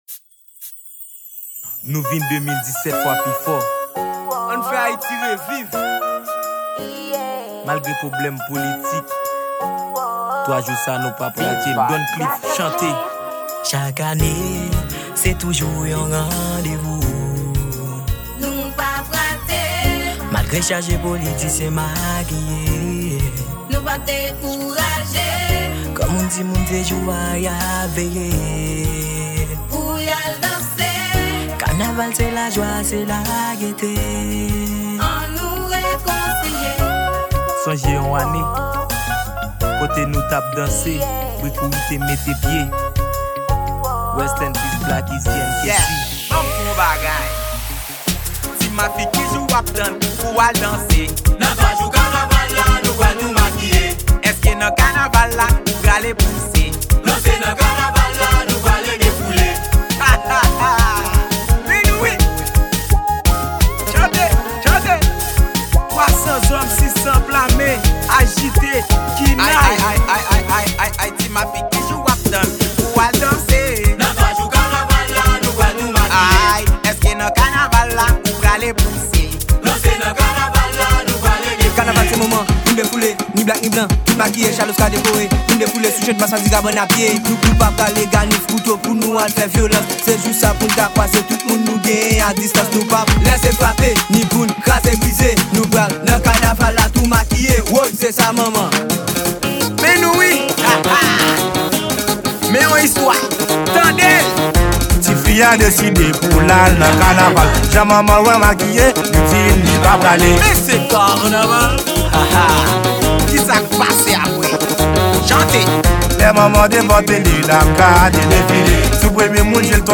Genre: KANAVAL 2017.